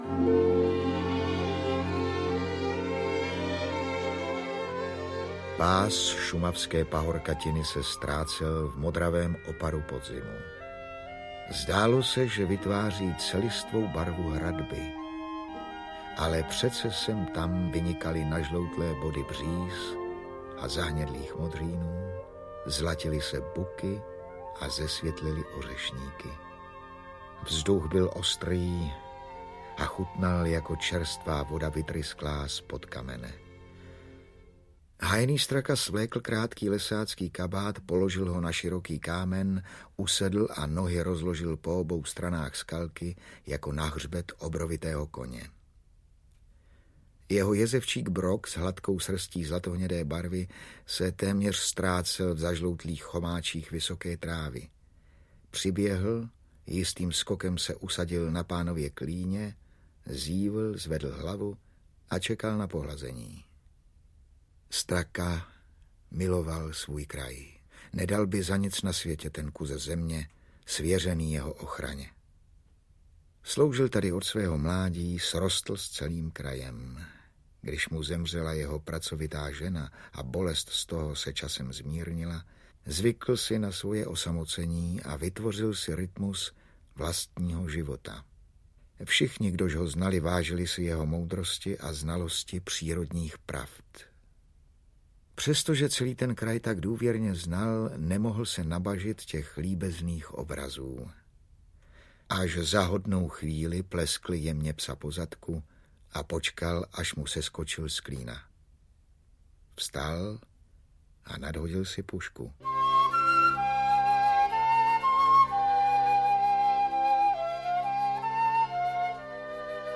Pod jezevčí skálou audiokniha
Ukázka z knihy